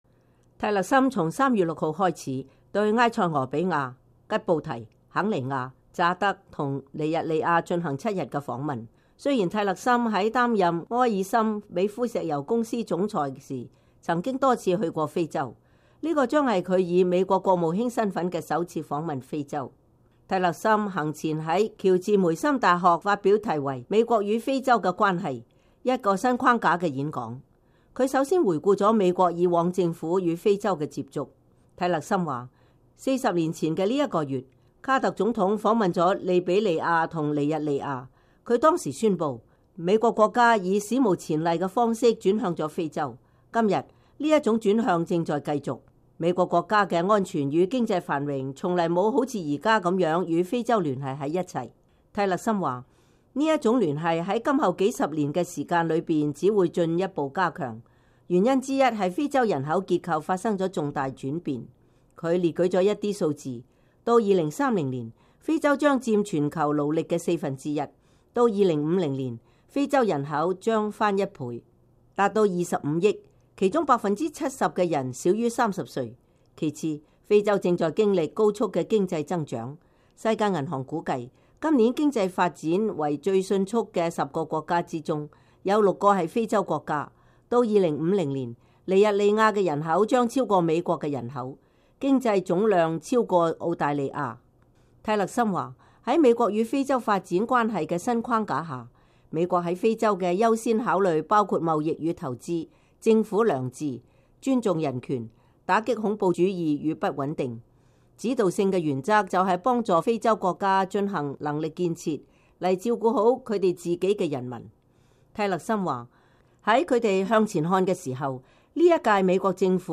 在啟程之前，蒂勒森在華盛頓郊外的喬治梅森大學發表演講，闡述川普政府與非洲發展關係的優先考慮和新的政策框架。他還表示，美國加強與非洲國家之間夥伴關係的目的是使這些國家實現其長遠的安全與發展目標，與中國在非洲的做法形成鮮明的對照。